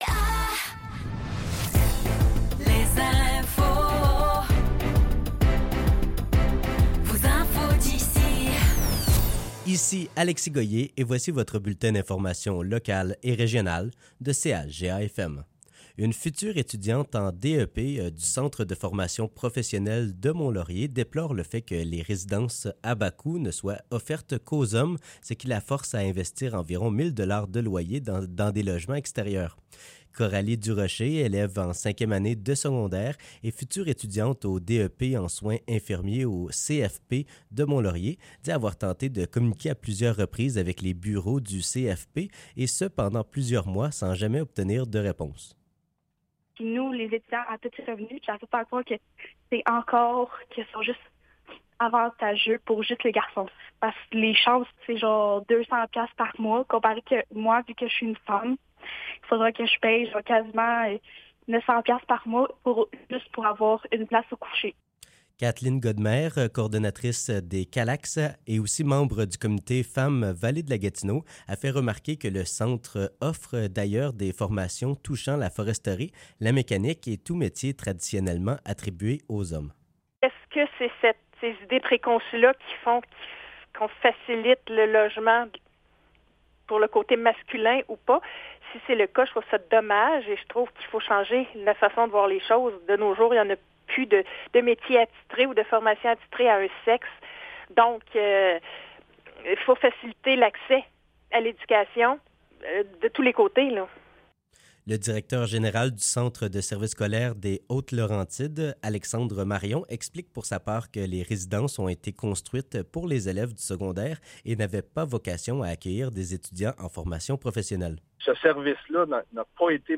Nouvelles locales - 20 février 2024 - 15 h